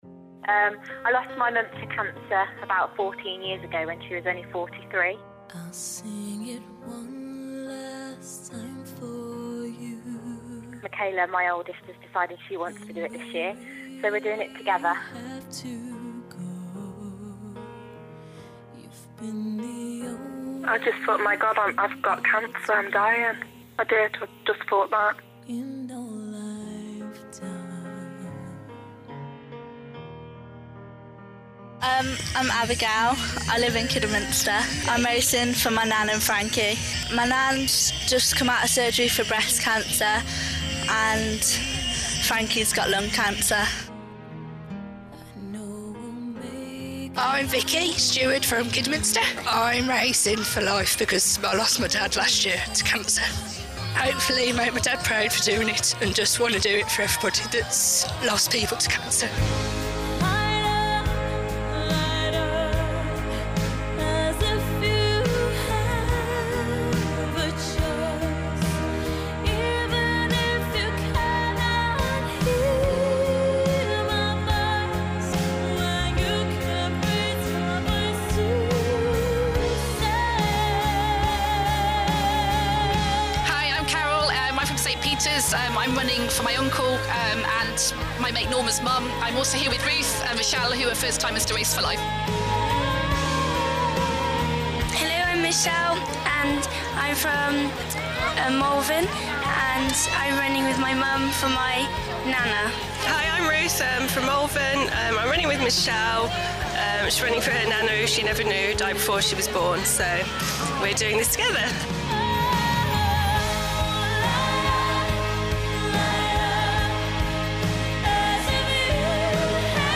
Race-for-Life-Run-Survivor-Montage
Race-for-Life-Run-Survivor-Montage.m4a